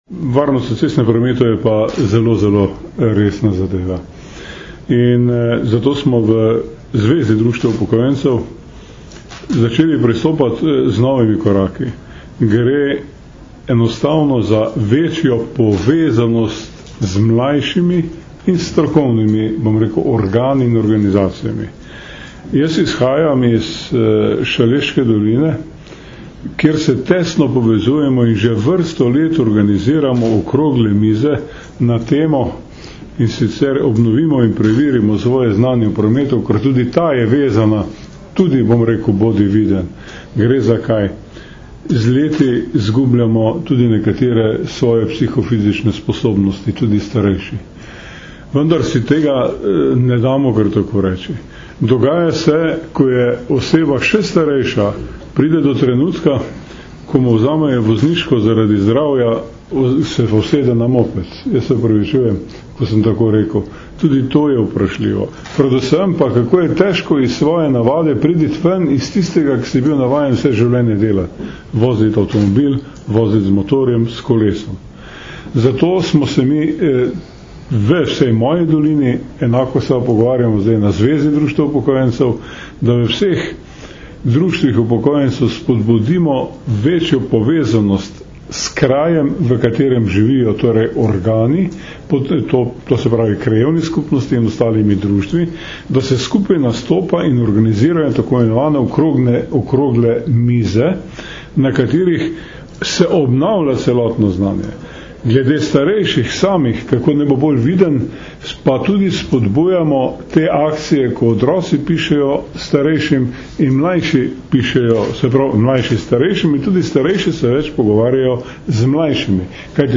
Na današnji novinarski konferenci, ki jo je organizirala Javna agencija RS za varnost prometa, smo predstavili akcijo za večjo varnost pešcev Bodi preViden, ki bo potekala med 1. in 14. oktobrom 2012.
Zvočni posnetek izjave